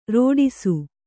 ♪ rōḍisu